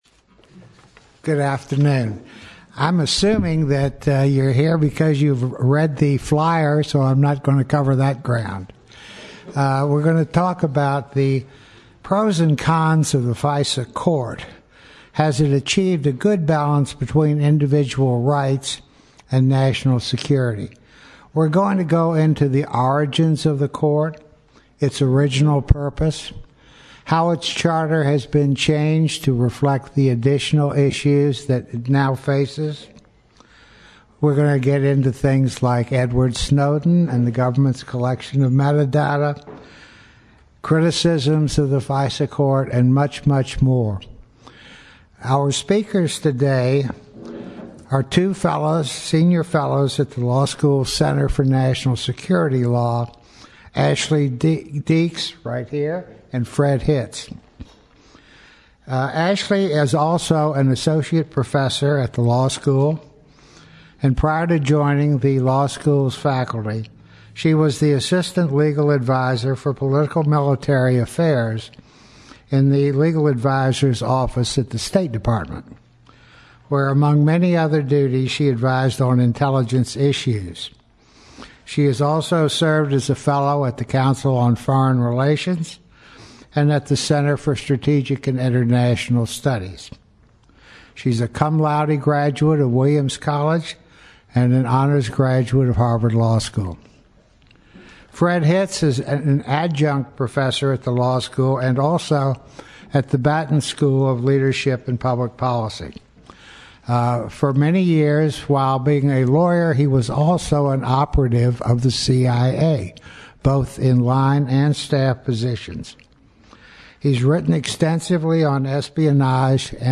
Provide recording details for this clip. The meeting was held at the Senior Center in Charlottesville . Following the presentation, questions were taken from the audience.